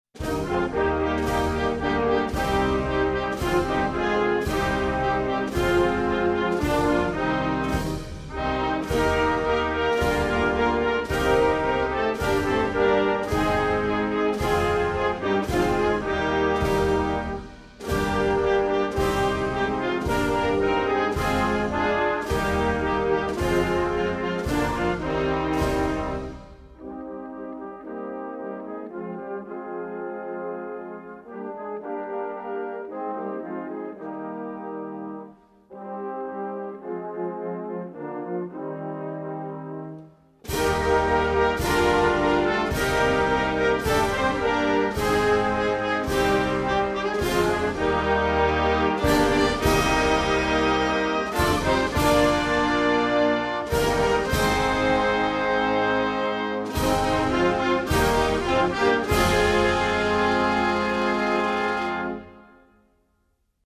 Jana_Gana_Mana_instrumental.mp3